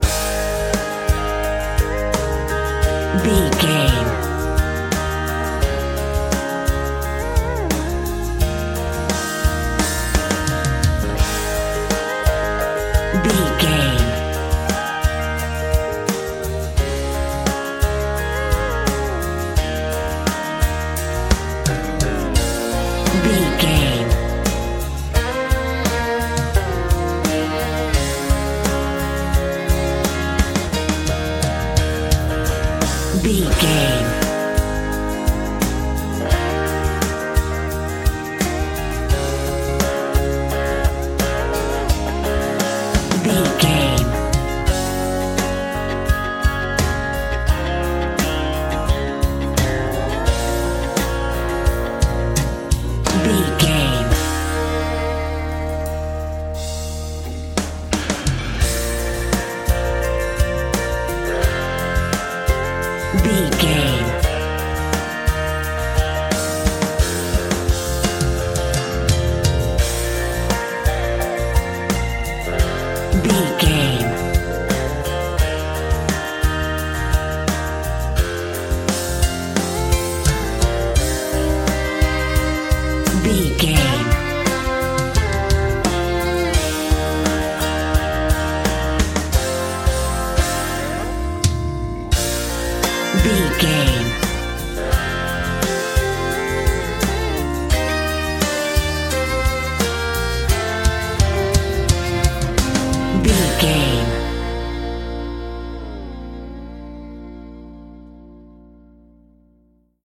Ionian/Major
A♭
acoustic guitar
electric guitar
drums
violin